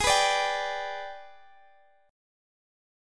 AbmM7b5 Chord
Listen to AbmM7b5 strummed